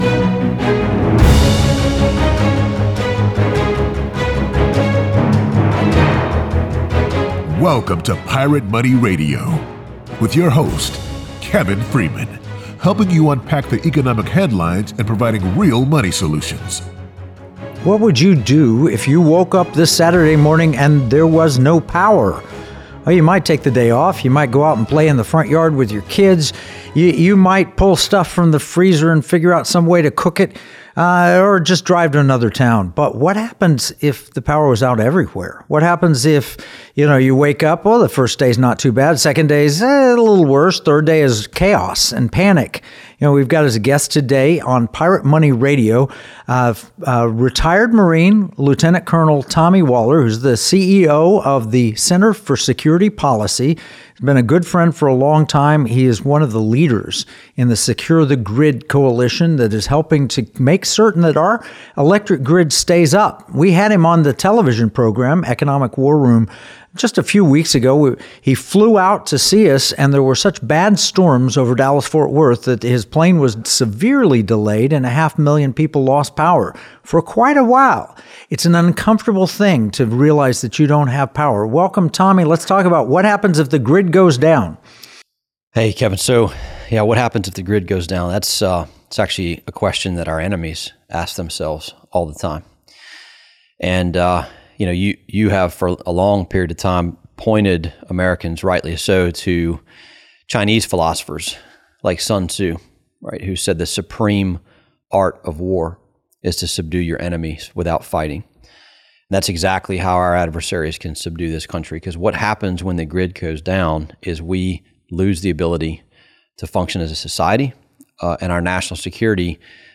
Featuring a new documentary narrated by Dennis Quaid, "Grid Down, Power Up," this discussion dives into practical solutions and the urgent need for political action to safeguard our electrical infrastructure. Learn how you can get involved to ensure a secure and resilient power supply for future generations.